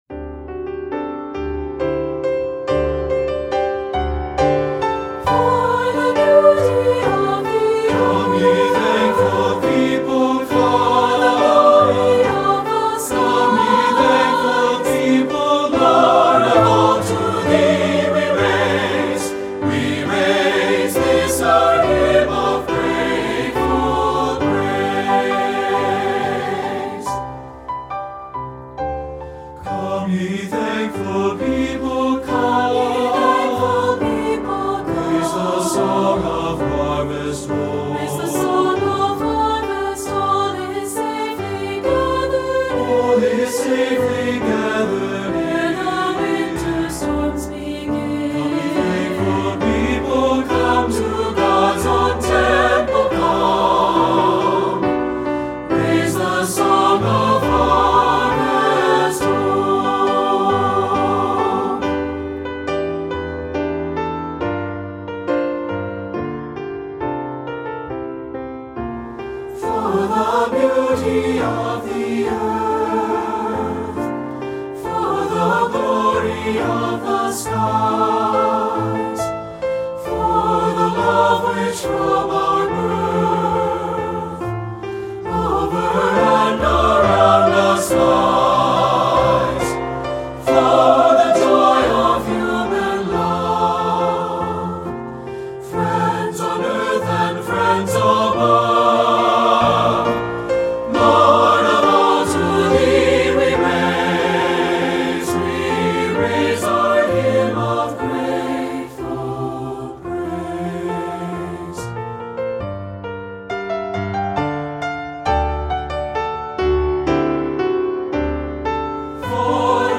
Voicing: SA(T)B and Piano